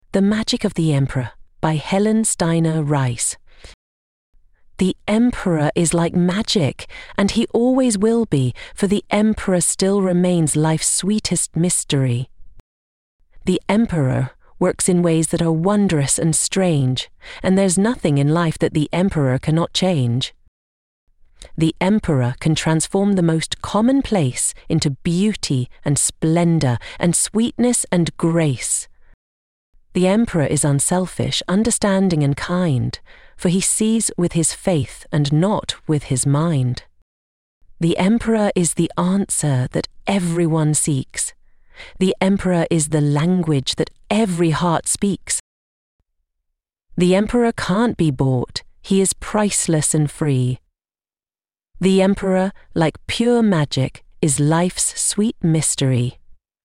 Just for fun, I recorded the altered version and sent it through.